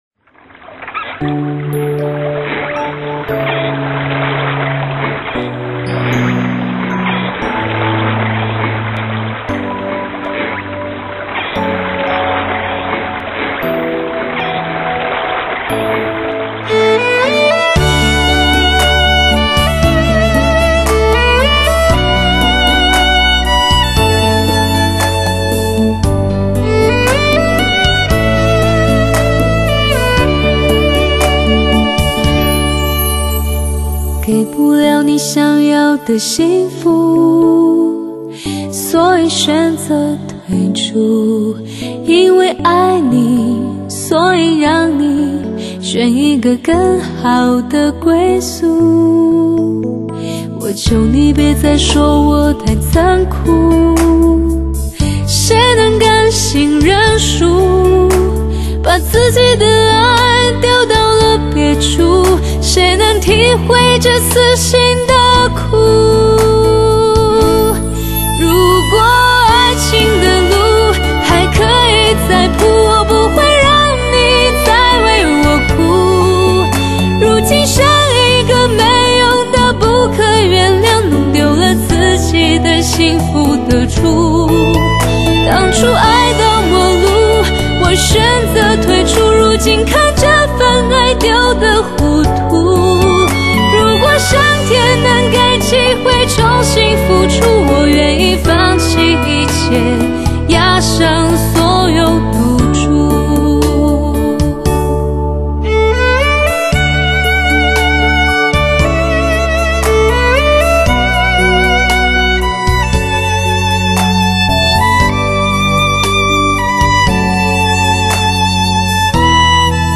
国际音响协会多声道音乐录音典范，1:1德国母盘直刻技术，
塑造无比传真的高临场音效，极富视听效果的发烧靓声，
音色淳厚优美，典雅华丽而委婉，发挥音乐的极至......